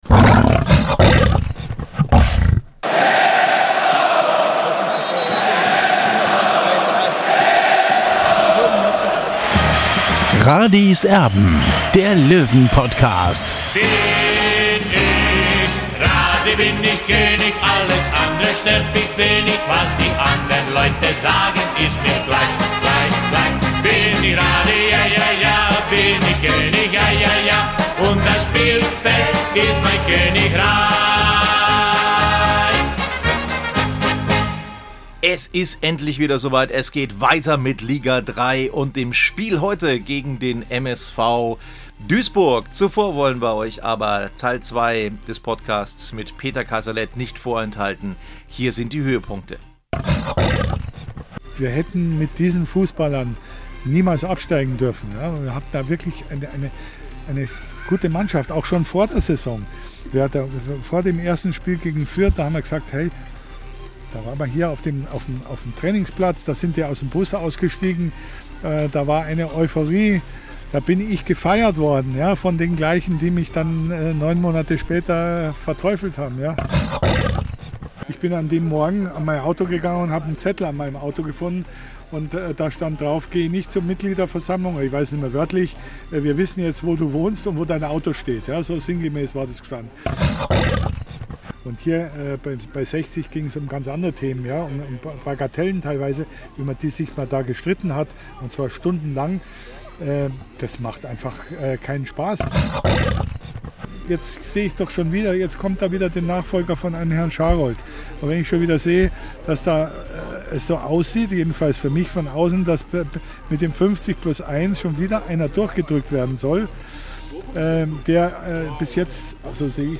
Im zweiten Teil des großen Interviews